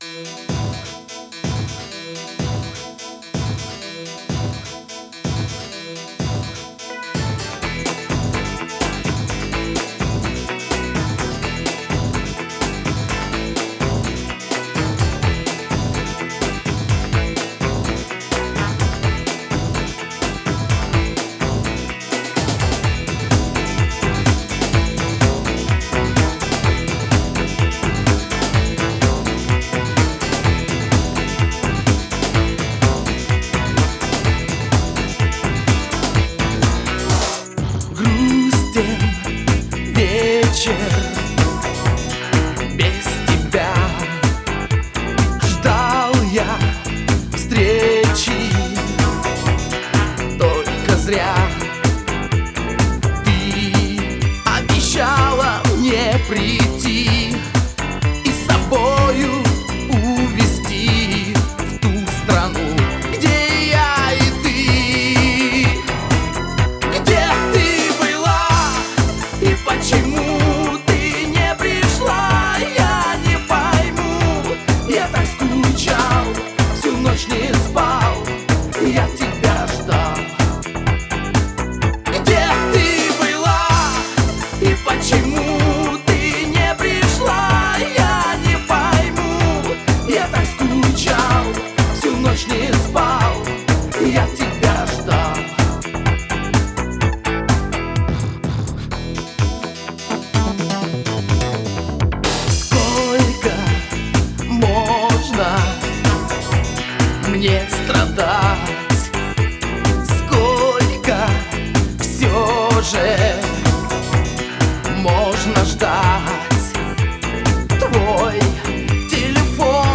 Жанр: Попса